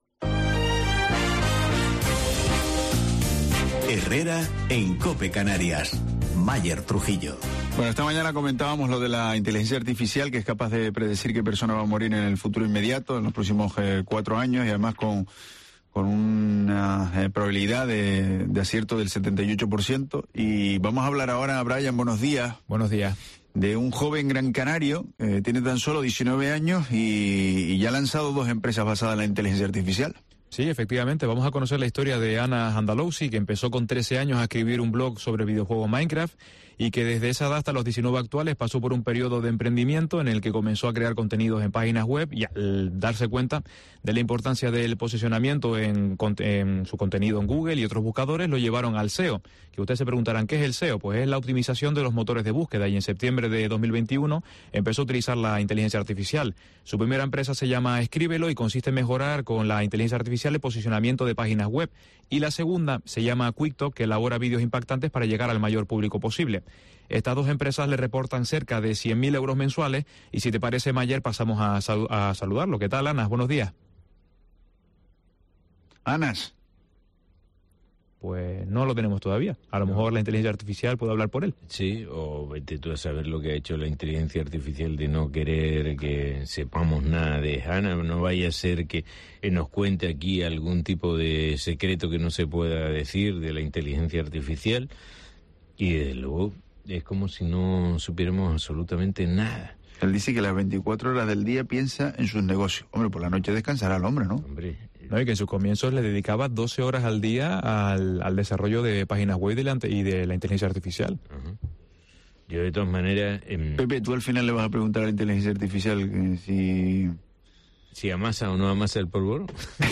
En nuestros micrófonos aseguraba que ahora trabaja para formar a la gente en IA, algo a lo que todos nos vamos a tener que adaptar, porque la carga de trabajo se va a reducir al automatizarse muchos procesos, lo que podría poner en riesgo muchos puestos de trabajo.